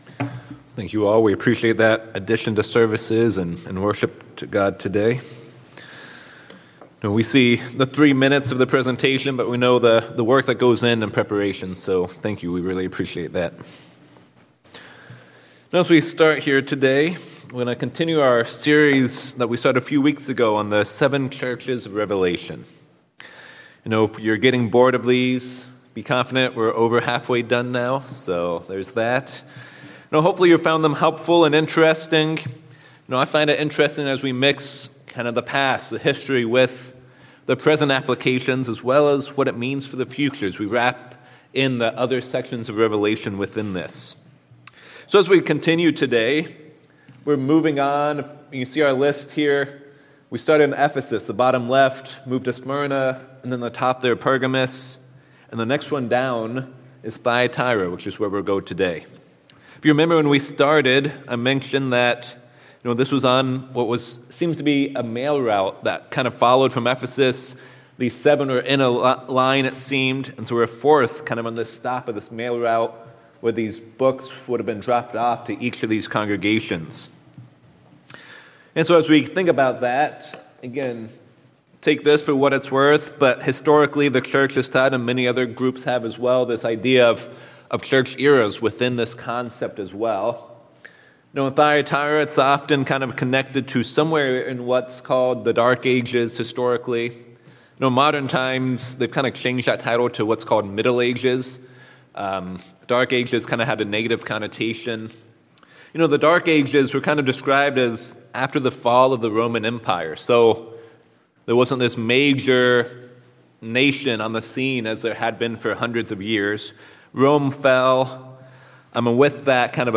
In part four of the sermon series on the seven churches, we will review the letter to the church of Thyatira. In this letter, the church is known for its good works, but at the same time, it had problems tolerating the false prophetess Jezebel. We will learn the importance of not tolerating and accepting sin.